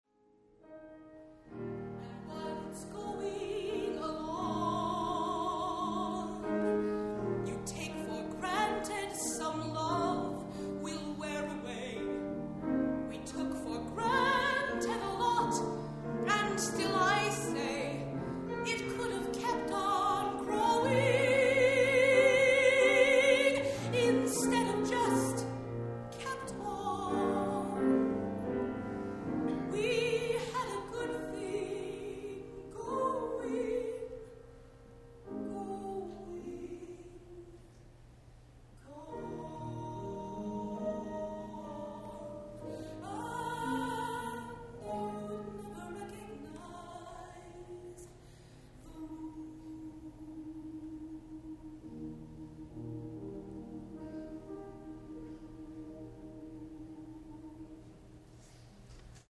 (Ballad)